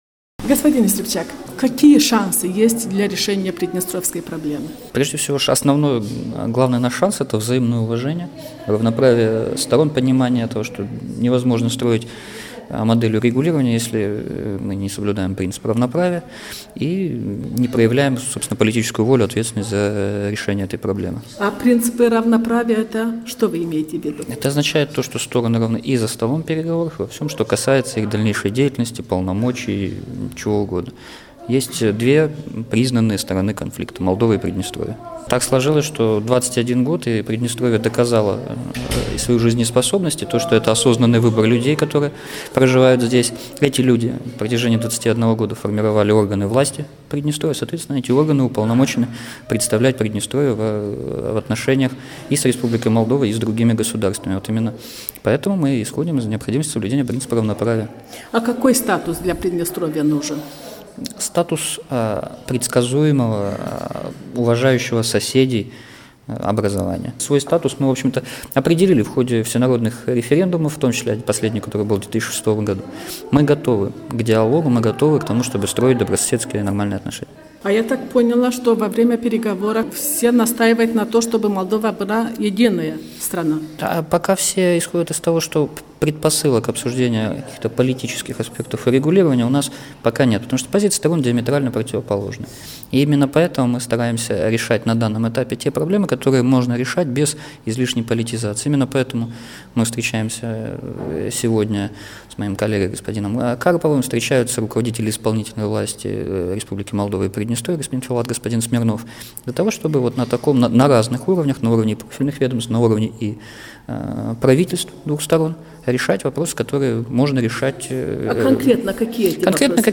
Министр иностранных дел непризнанной приднестровской республики, Владимир Ястребчак, отвечает на вопросы Радио Свободная Европа.
Интервью с Владимиром Ястребчаком